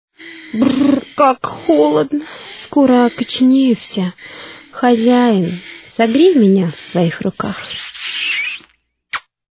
• Пример реалтона содержит искажения (писк).